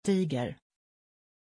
Aussprache von Tiger
pronunciation-tiger-sv.mp3